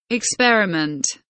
experiment kelimesinin anlamı, resimli anlatımı ve sesli okunuşu